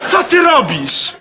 Worms speechbanks
oinutter.wav